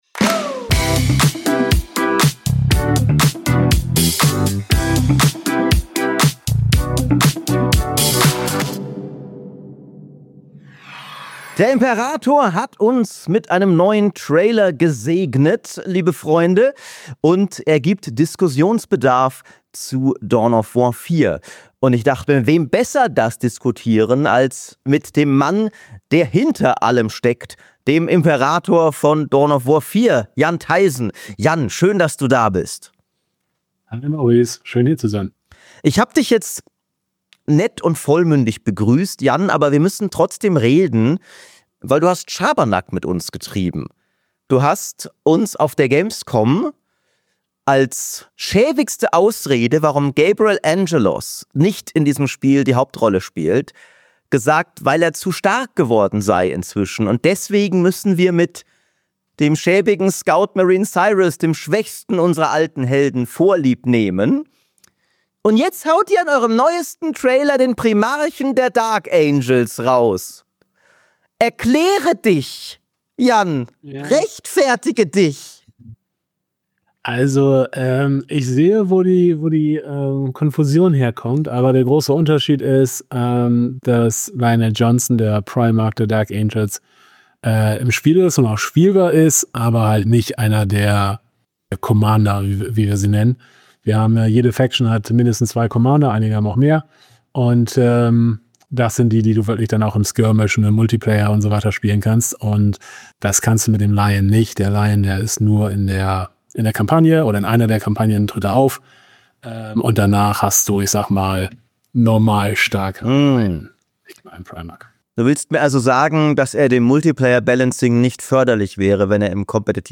Exklusives Interview: So groß wird Dawn of War 4 wirklich!